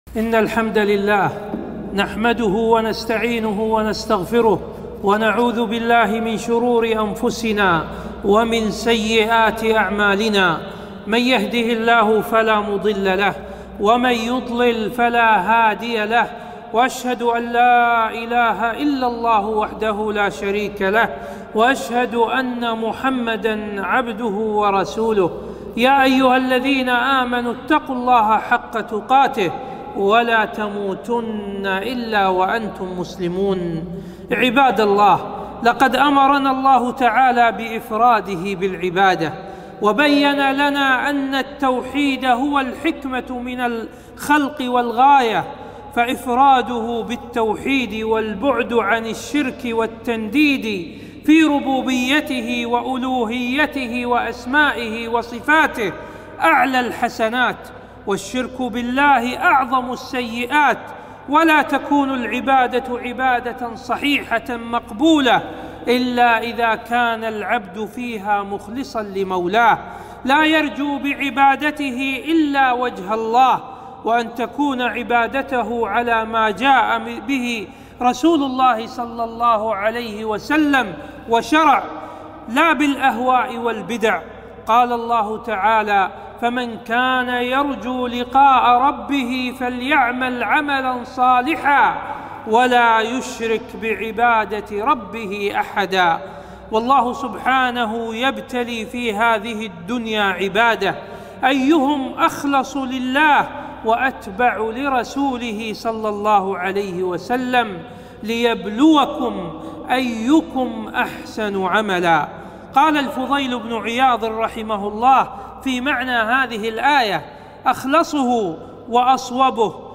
خطبة - خطبة الرياء وخطورته